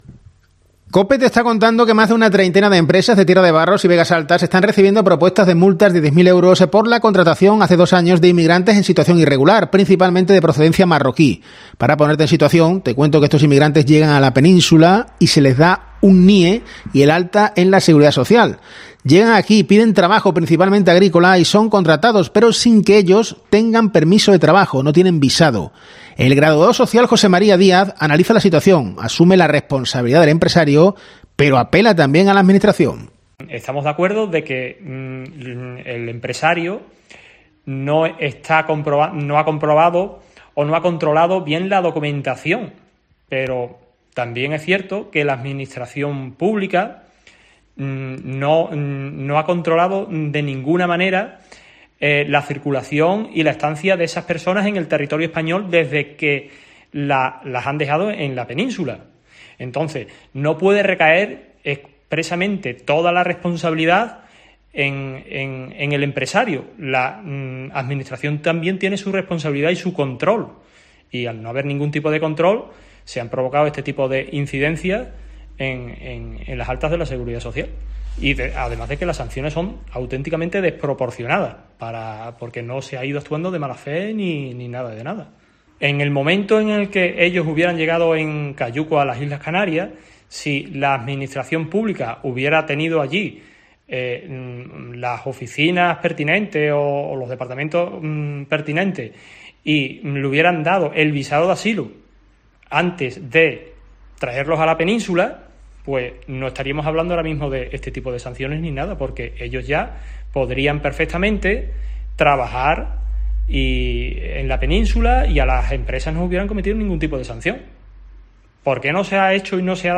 Uno de ellos ha hablado en COPE.